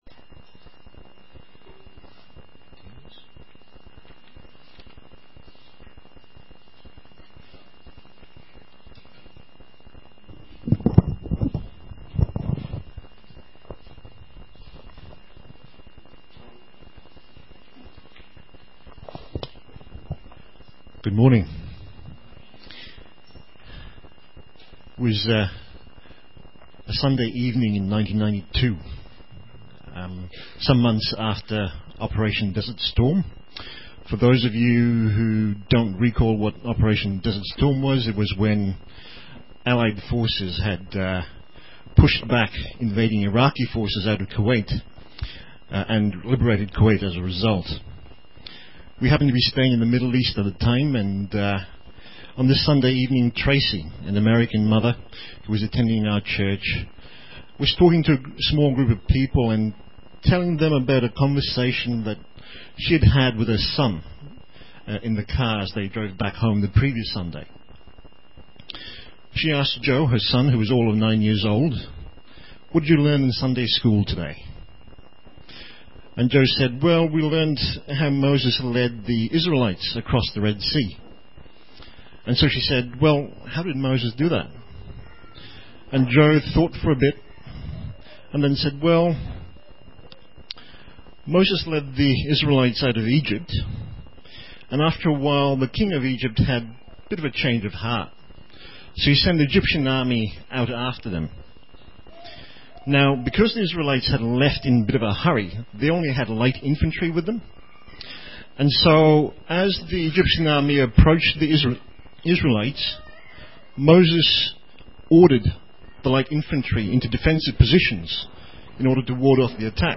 Psalm 23 Listen to the sermon here.
Categories Sermon Tags psalms